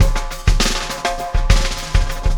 Extra Terrestrial Beat 12.wav